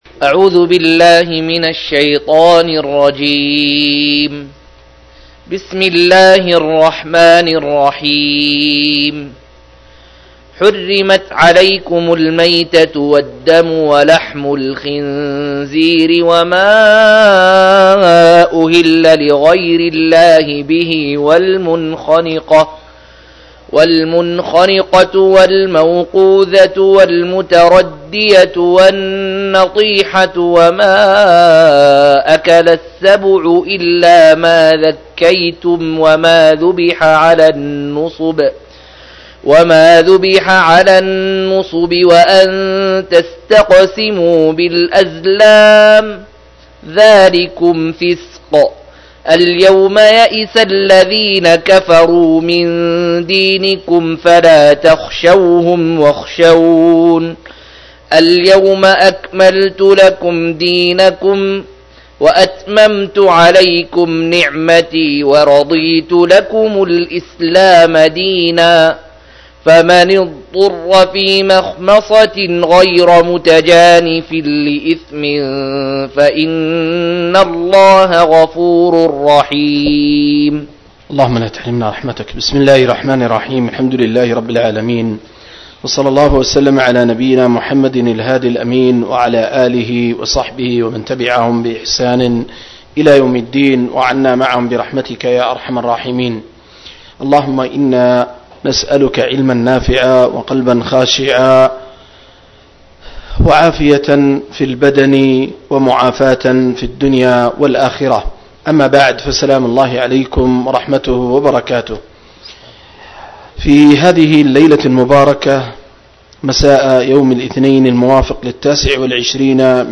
108- عمدة التفسير عن الحافظ ابن كثير رحمه الله للعلامة أحمد شاكر رحمه الله – قراءة وتعليق –